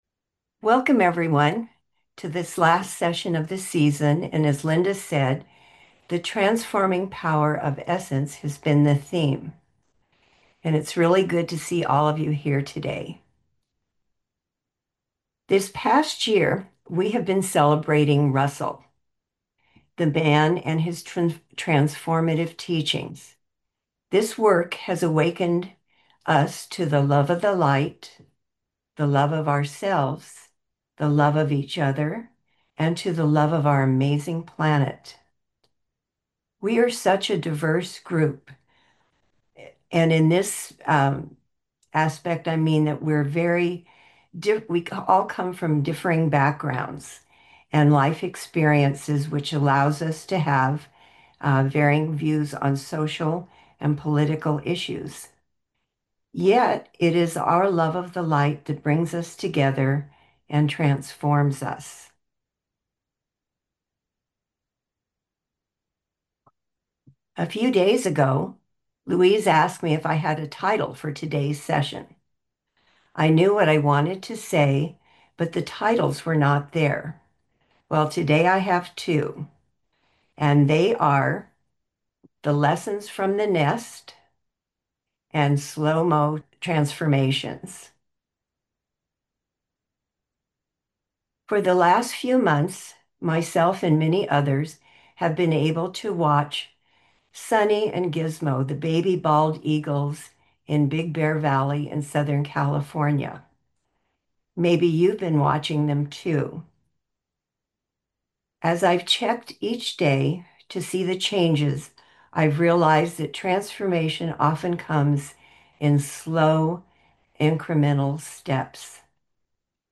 Via Zoom